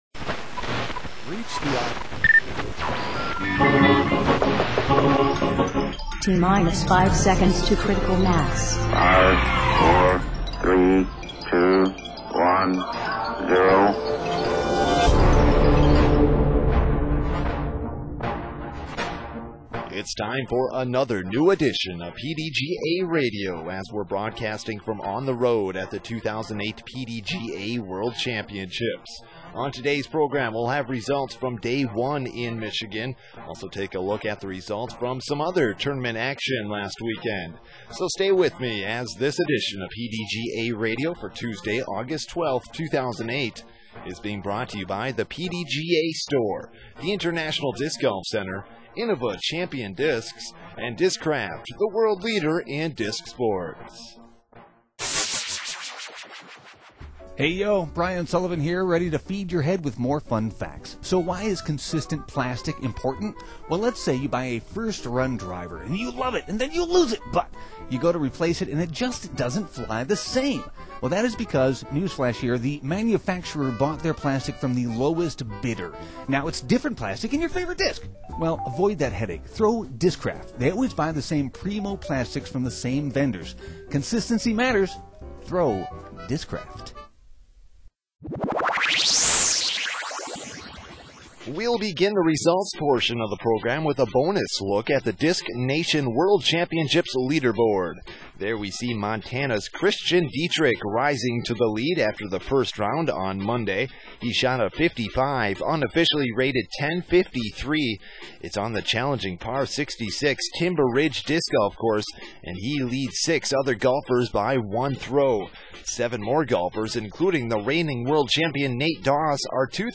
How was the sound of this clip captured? August 12, 2008: PDGA Radio is broadcasting from the 2008 PDGA World Championships with results from day one in Michigan and the distance contest's results. Also a look at the results from some other tournament action last weekend and otherwise keeping you up to date with disc golf's largest event.